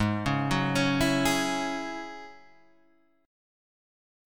G#7sus4#5 Chord